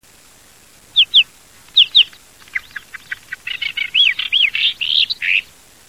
głosy innych białorzytek